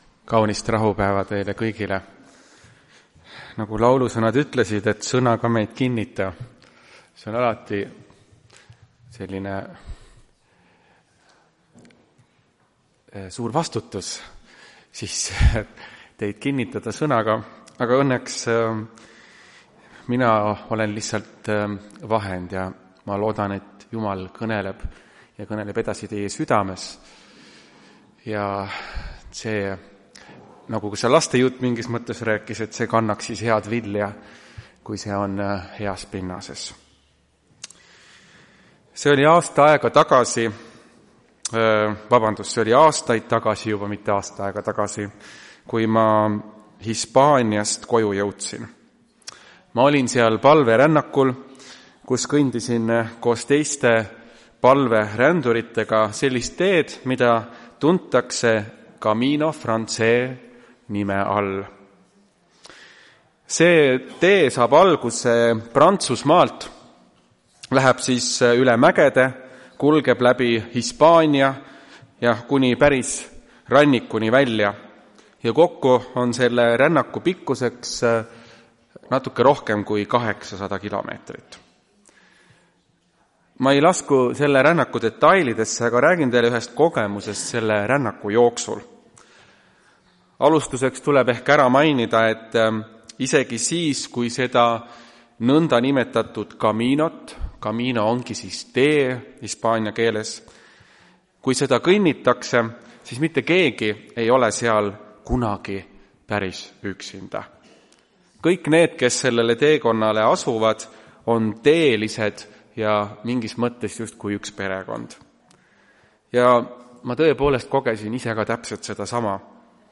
Tartu adventkoguduse 05.04.2025 hommikuse teenistuse jutluse helisalvestis.